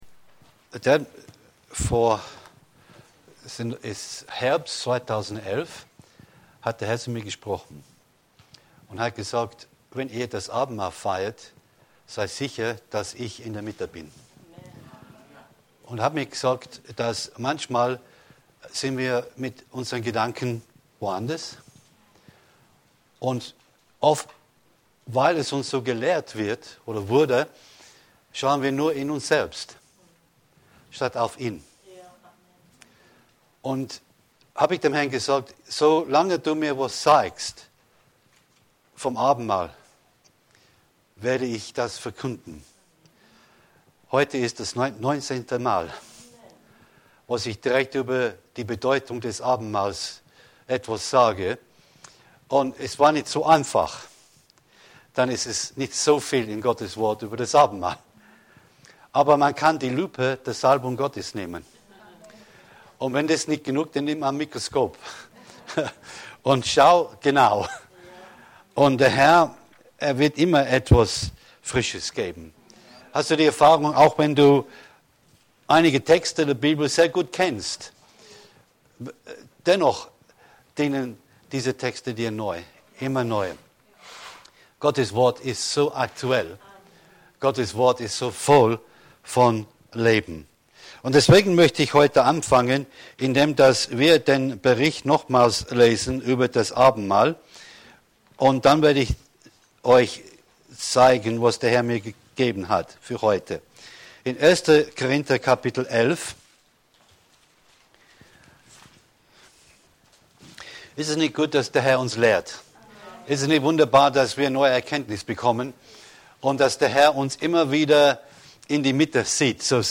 Info Info Die Herrlichkeit des Herrn schauen 01.09.2020 Predigt herunterladen